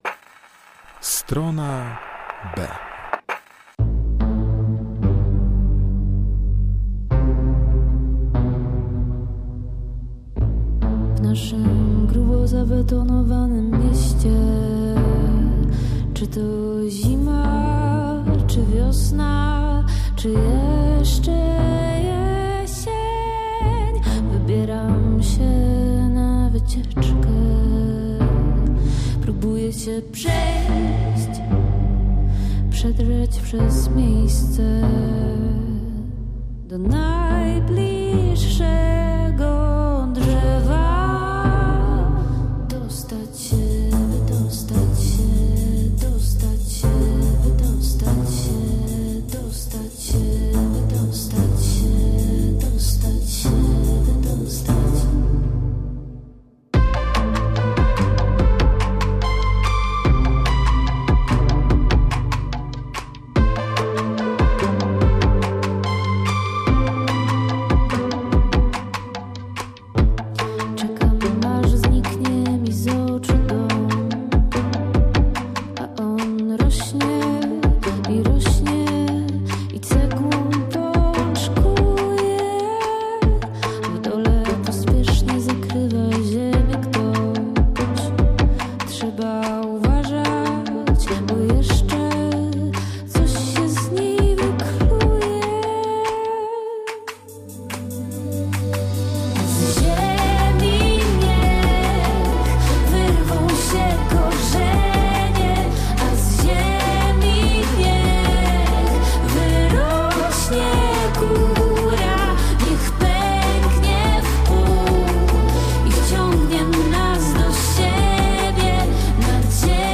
STRONA B - godzina z elektroniką, ambient, post punkiem i shoegaze.